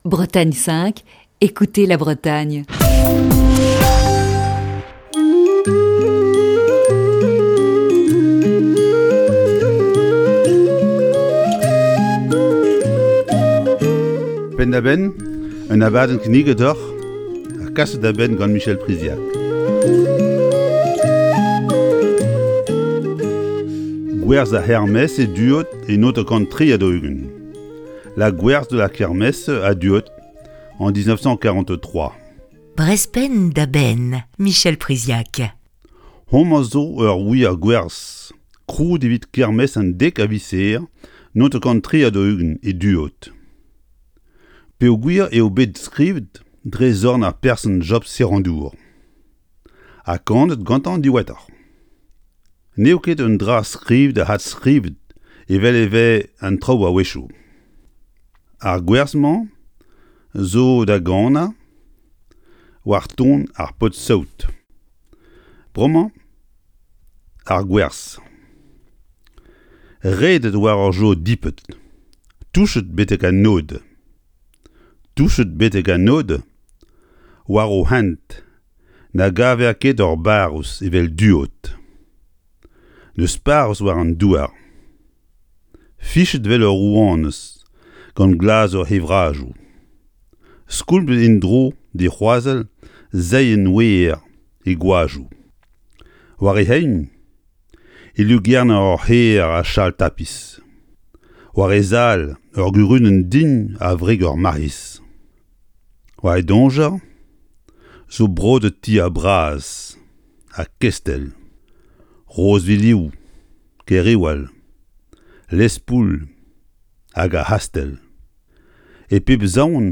Chronique du 19 mars 2021.